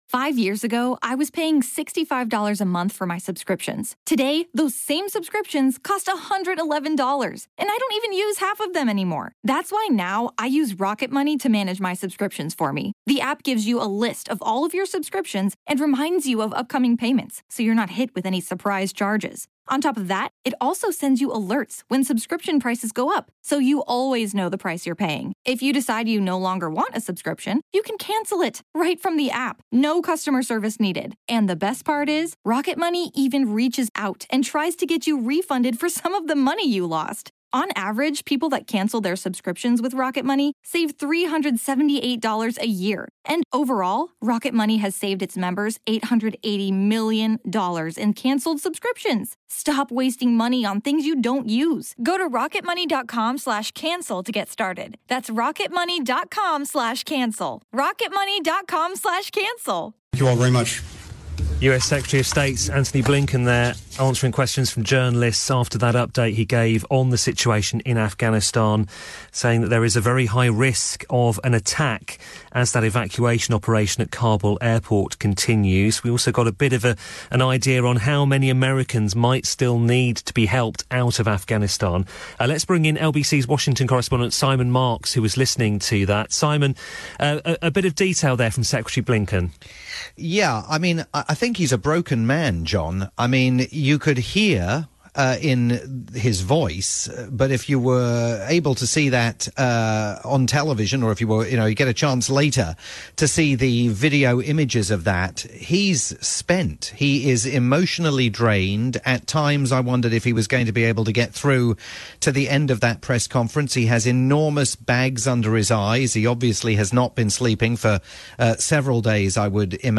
live report on the Secretary of State's agonising press conference in Washington.